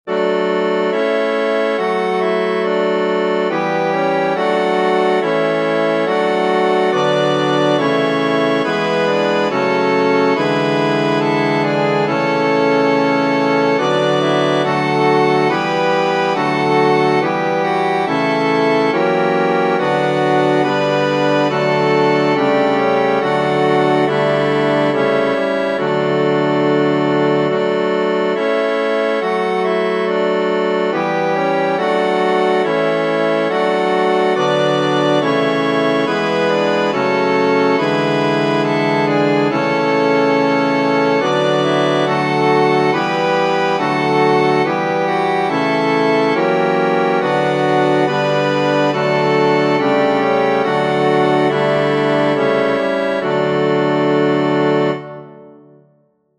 Tradizionale Genere: Religiose O Maria, Virgo pia, Mater admirabilis; Per te Deus, judex meus, Mihi sit placabilis.